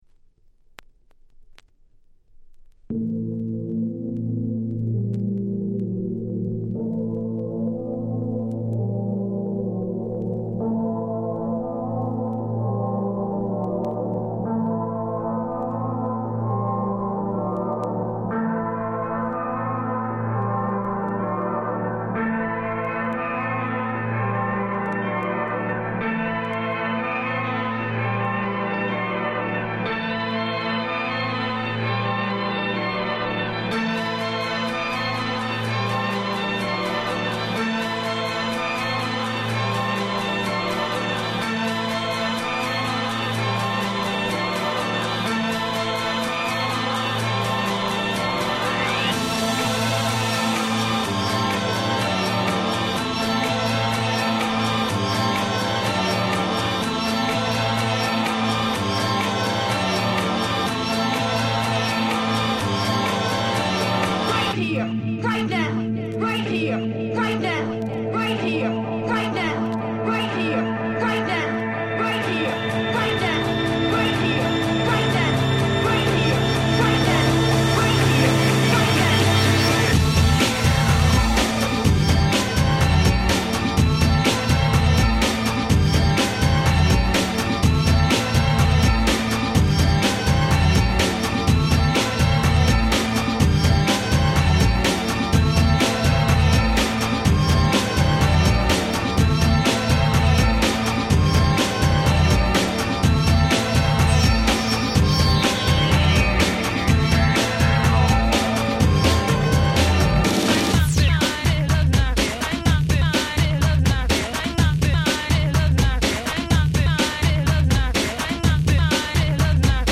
Break Beats Classic !!